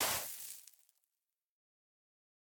brush_sand_complete1.ogg